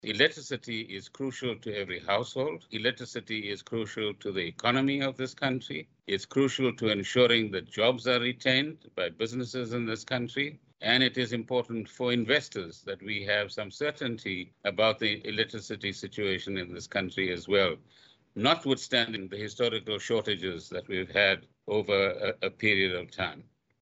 Gordhan yesterday, during a press briefing, announced that a wage agreement was reached yesterday between unions and Eskom.